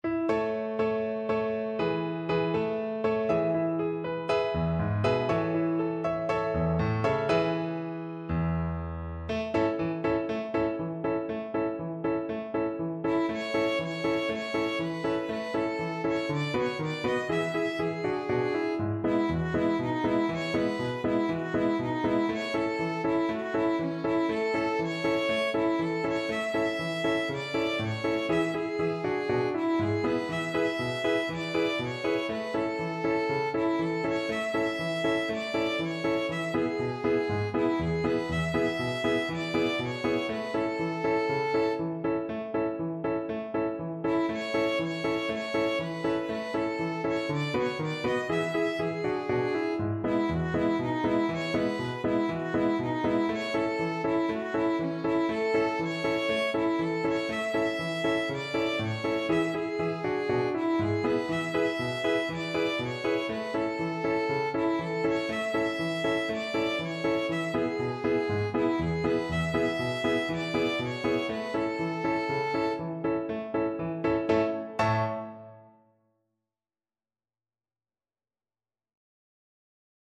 Free Sheet music for Violin
2/4 (View more 2/4 Music)
Db5-E6
A major (Sounding Pitch) (View more A major Music for Violin )
Allegro (View more music marked Allegro)
el_rancho_grande_VLN.mp3